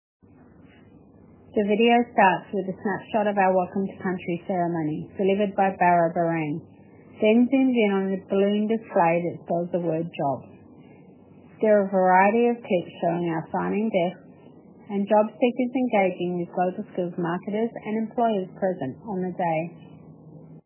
Audio Description
CCJobsExpoVideoAudioDescription.mp3